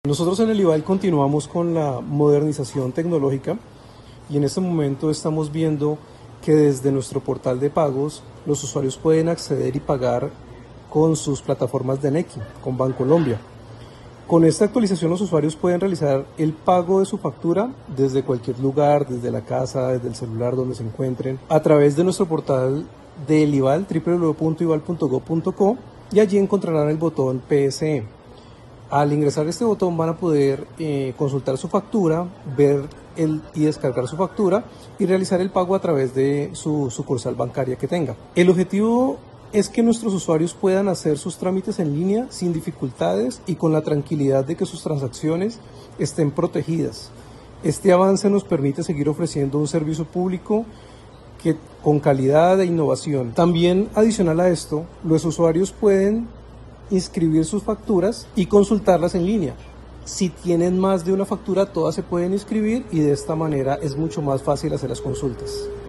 Transcripción comunicado de prensa